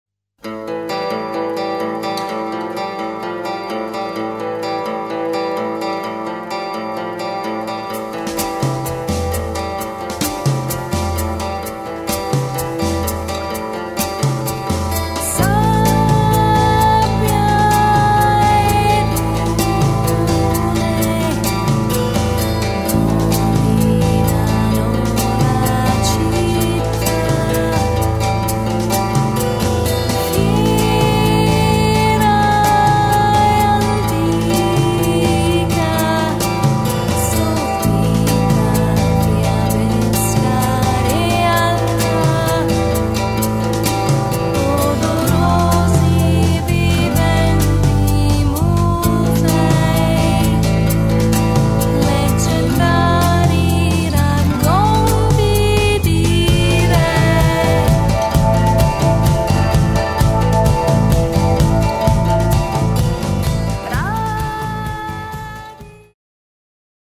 Il terzo esempio è riferito ad un mastering su un mix finale di musica etno rock
Ascolto Mastering